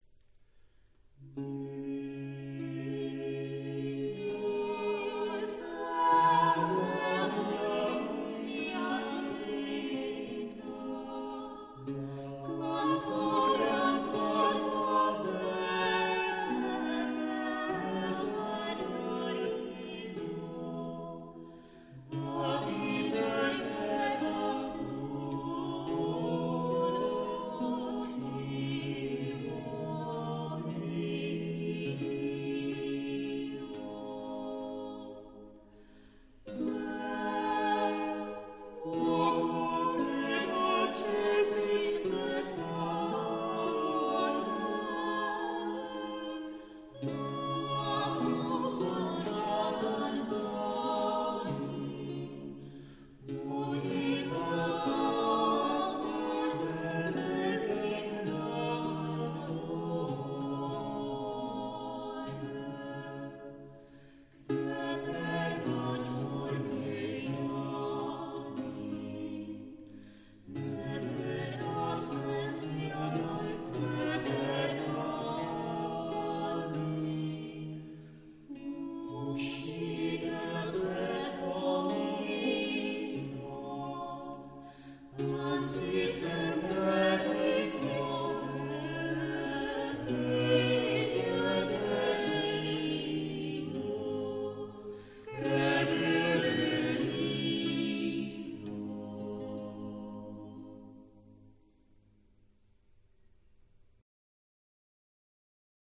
• Italské renesanční madrigaly
Natočeno v Thunské kapli v Děčíně v červnu 2005, loutna